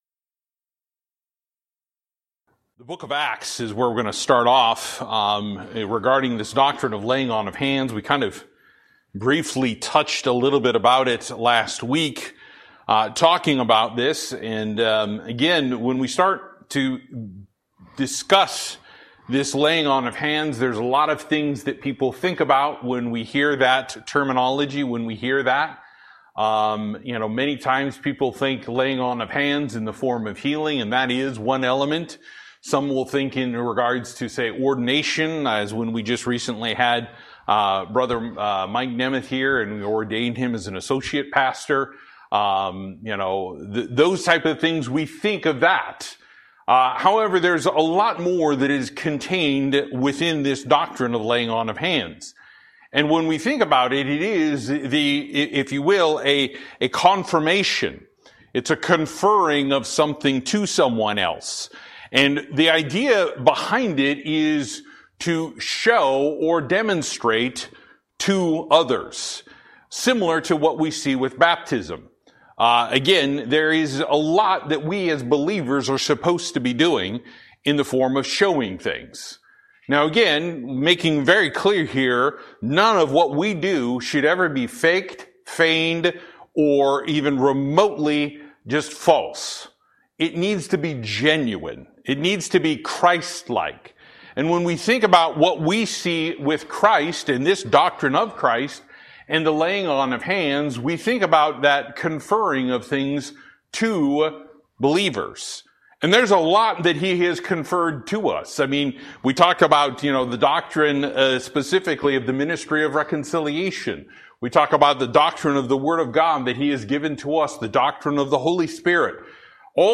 Service: Wednesday Night